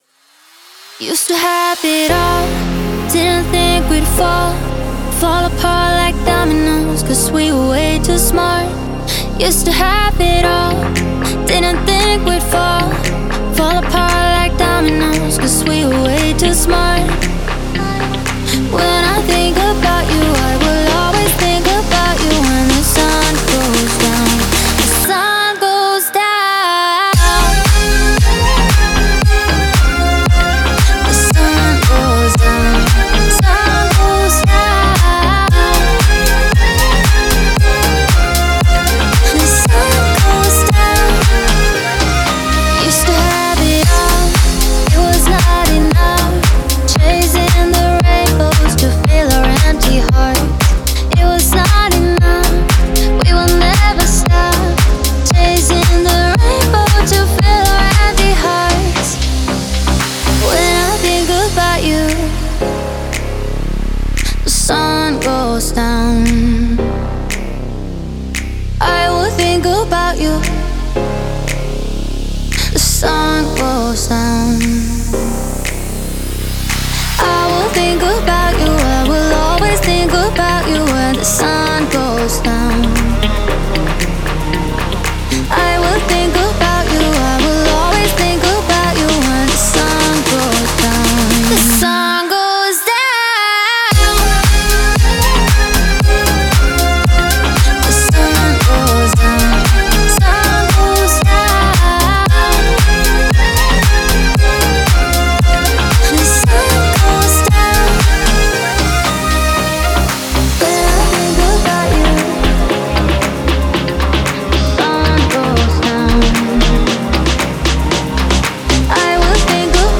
это зажигательный трек в жанре поп и EDM